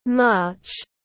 Tháng 3 tiếng anh gọi là march, phiên âm tiếng anh đọc là /mɑːtʃ/
March /mɑːtʃ/